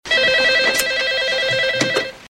• OLD DIGITAL TELEPHONE RING.wav
OLD_TELEPHONE_RING_nyS.wav